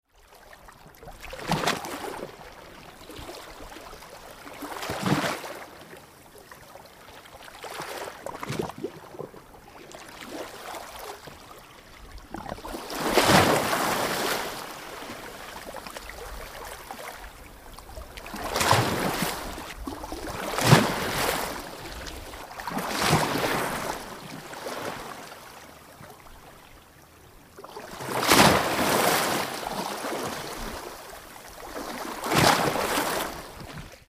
Mare con scogli
mare scogli.mp3